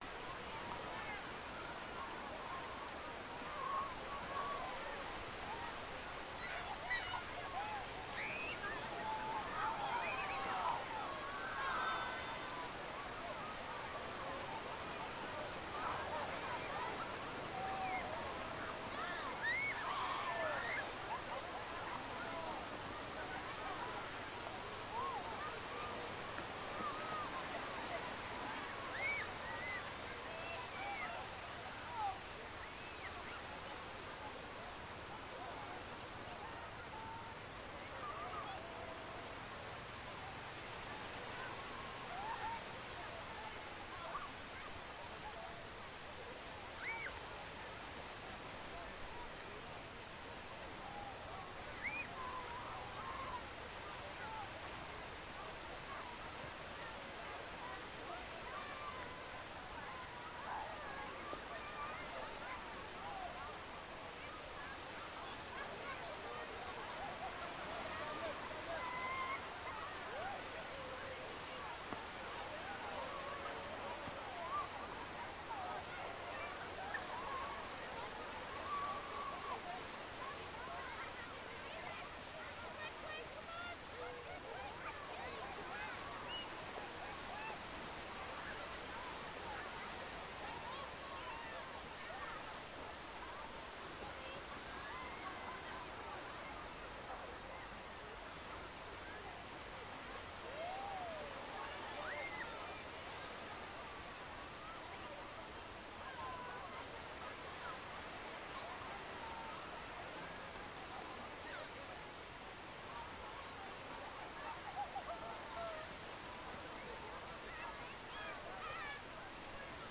海洋鸟类安静氛围
描述：海洋鸟类安静氛围
标签： 氛围 立体声 鸟类 大海 安静
声道立体声